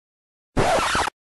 Record Scratch WITH download link